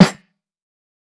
TC2 Snare 12.wav